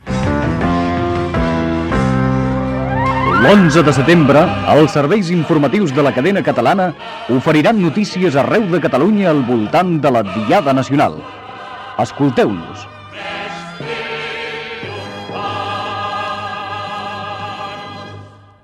Promo dels serveis informatius amb motiu de la Diada de l'11 de setembre.